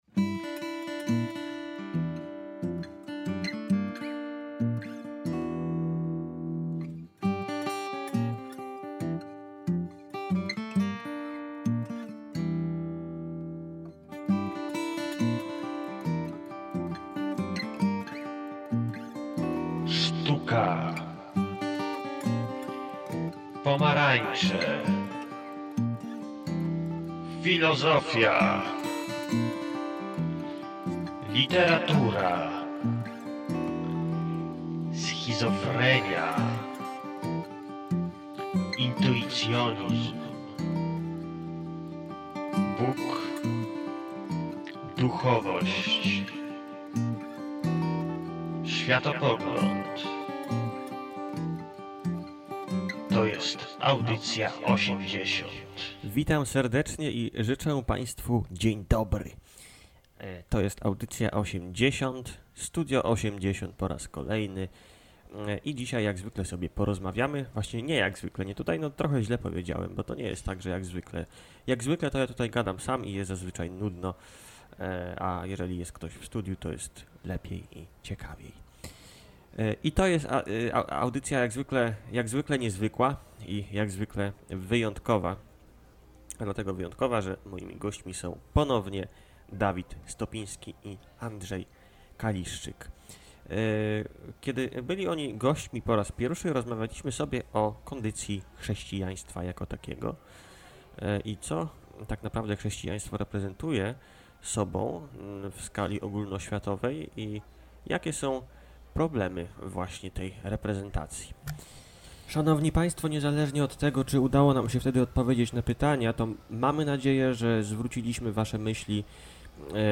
Jest to druga część rozmowy